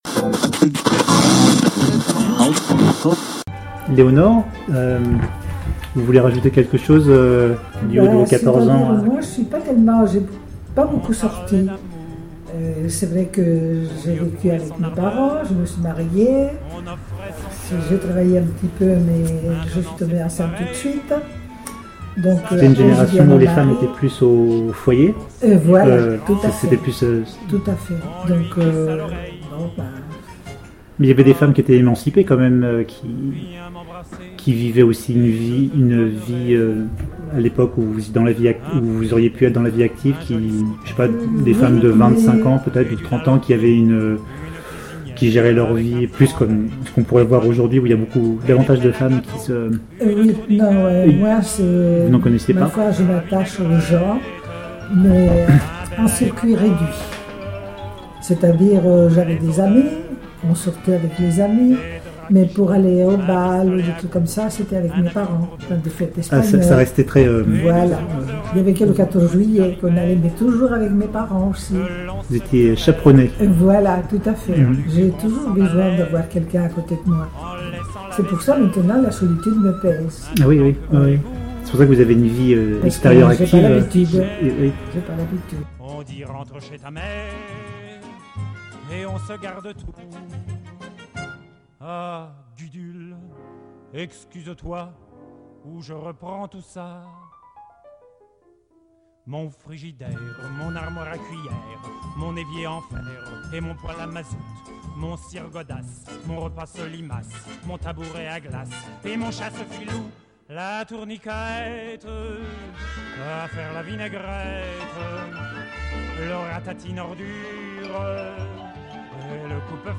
Débat : troisième partie